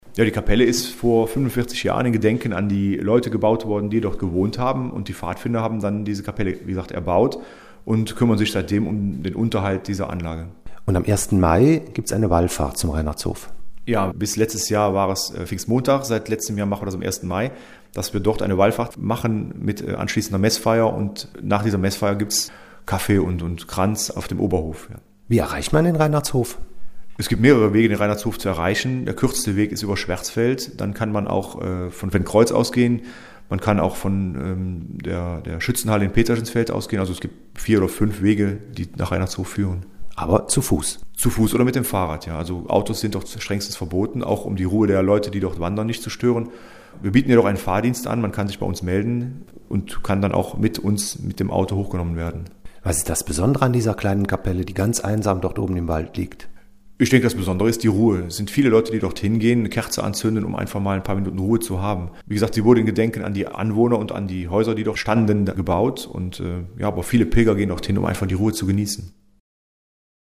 Radio Contact